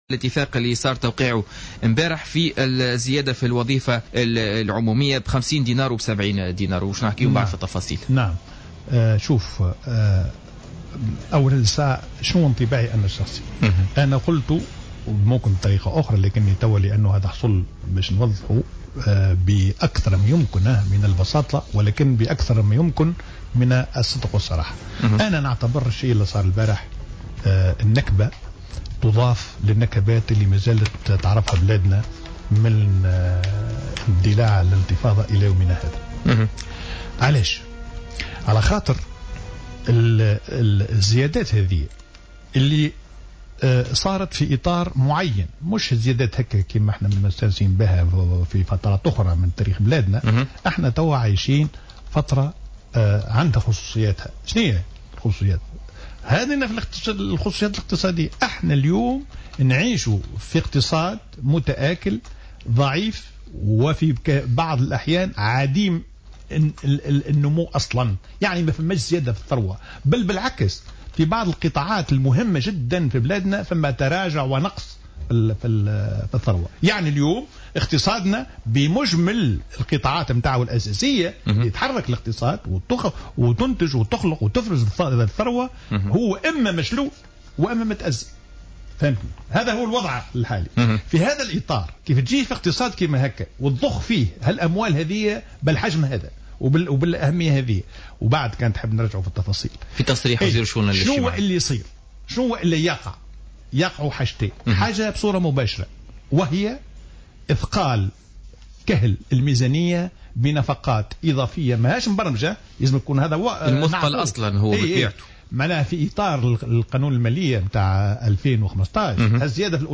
L’expert économique et ancien ministre des finances Houcine Dimassi a considéré ce vendredi 1er mai 2015 lors de son passage sur les ondes de Jawhara FM dans le cadre de l’émission Politica, que l’augmentation des salaires pour les agents de la fonction publique et le secteur public est une catastrophe.